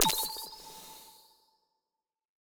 overlay-pop-out.wav